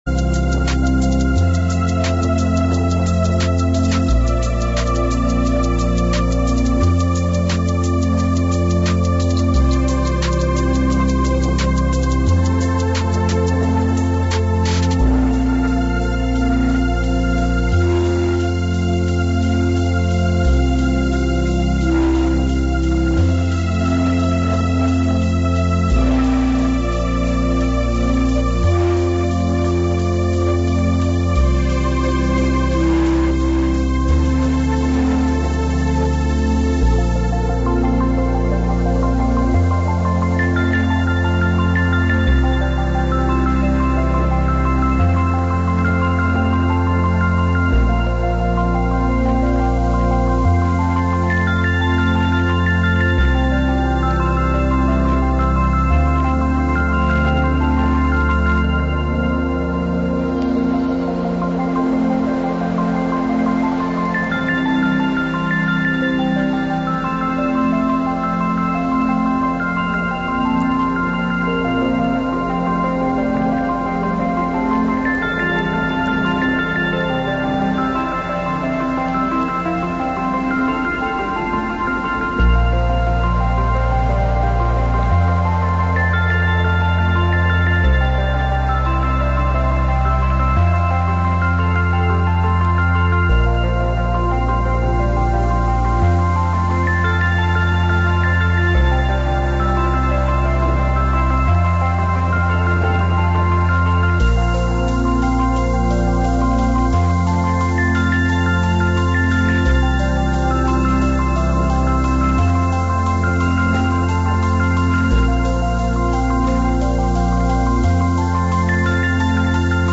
Informatiu que desplega una mirada àmplia sobre el territori, incorporant la informació de proximitat al relat de la jornada.